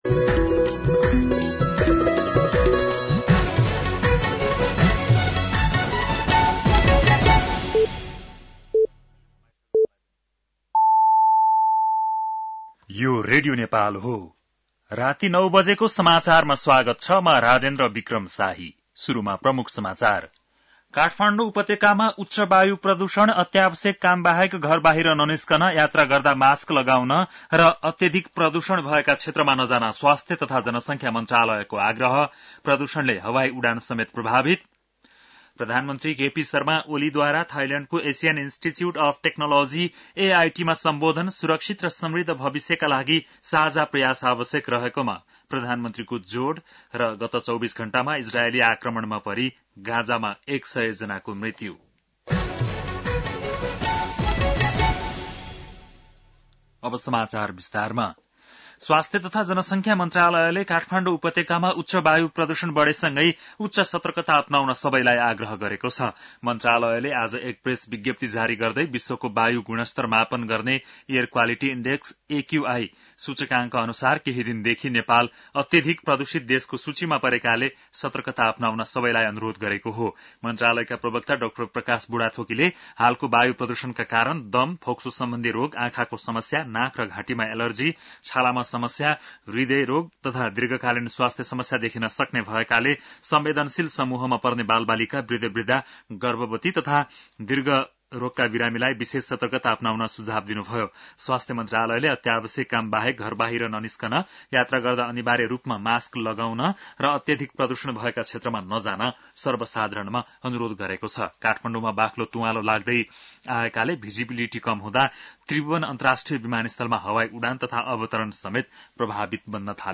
बेलुकी ९ बजेको नेपाली समाचार : २१ चैत , २०८१